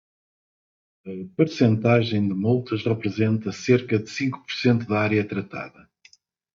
Brazilian Portuguese standard spelling of percentagem
Pronounced as (IPA)
/poʁ.sẽˈta.ʒẽj̃/